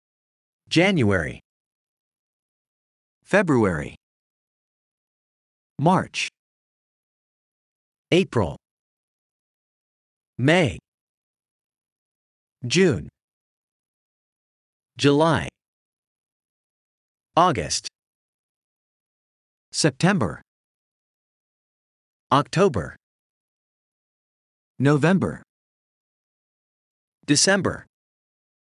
Ayrıca İngilizce ayların isimlerini doğru telaffuz edebilmeniz için mp3 formatında ses dosyası eklenmiştir.